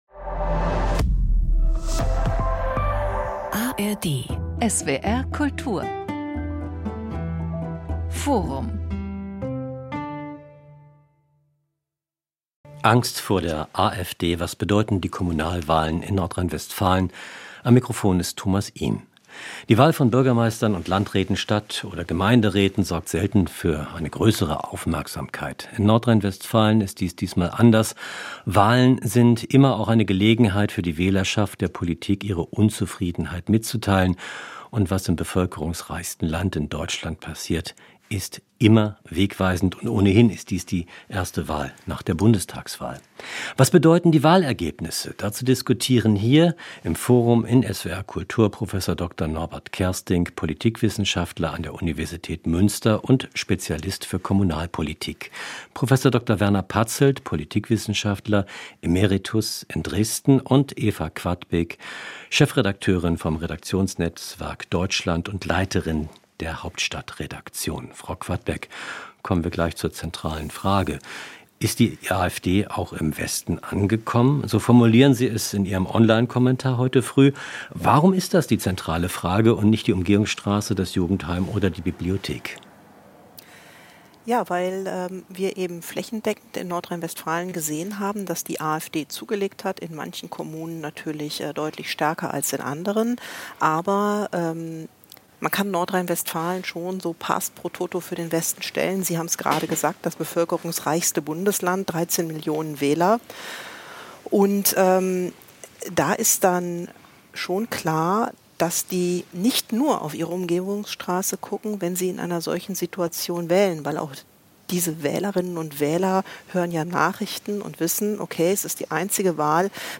Politikwissenschaftler